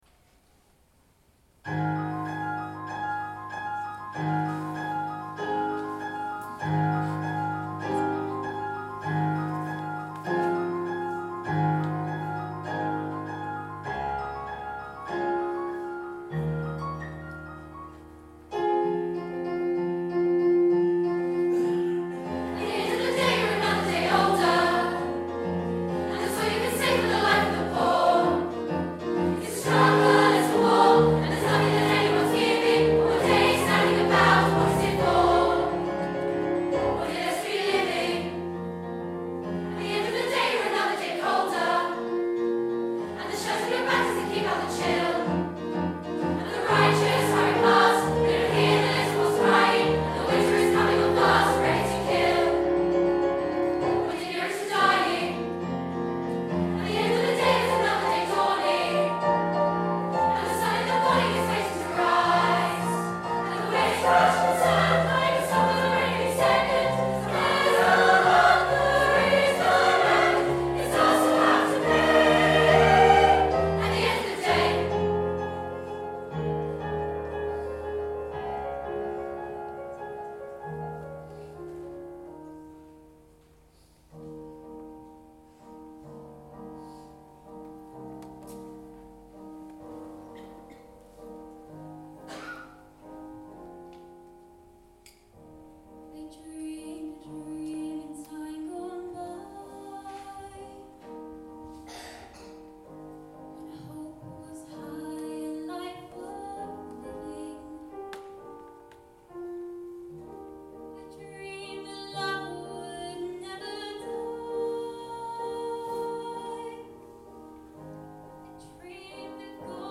Joint Choirs - Les Misérables